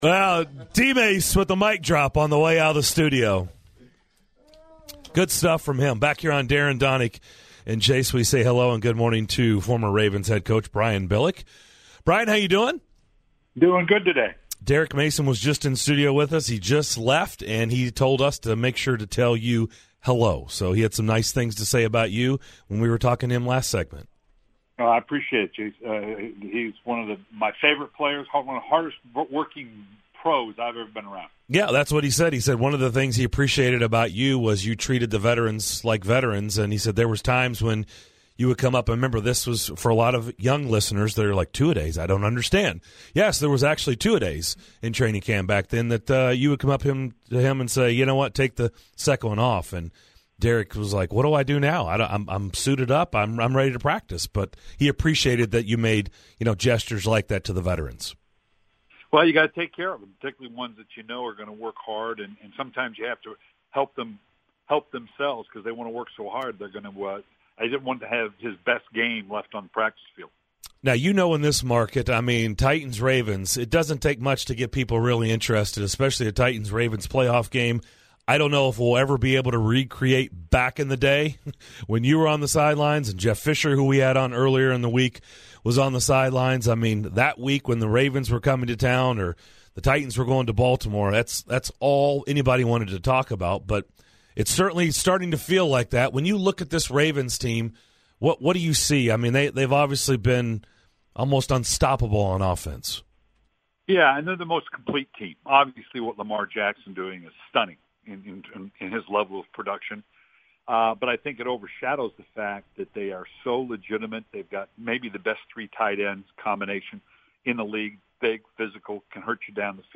Former NFL head coach Brian Billick joined the show to preview the Titans - Ravens matchup.